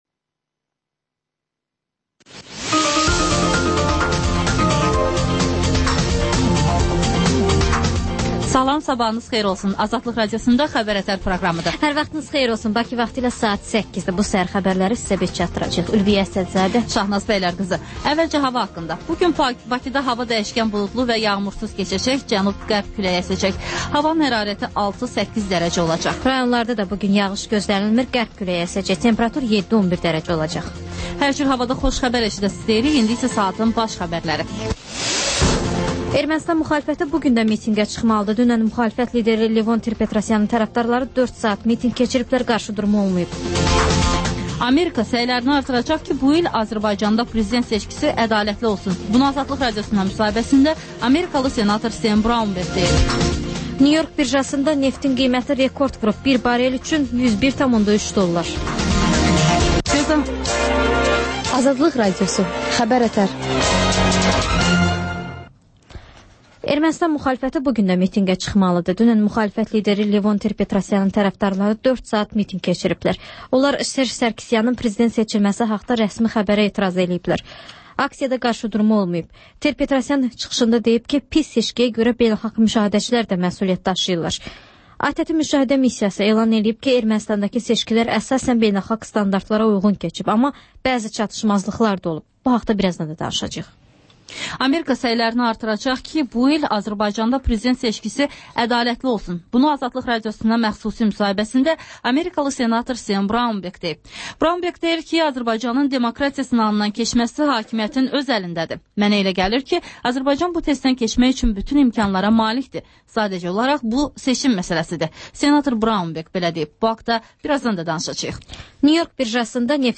Xəbər-ətər: xəbərlər, müsahibələr, sonda 14-24: Gənclər üçün xüsusi veriliş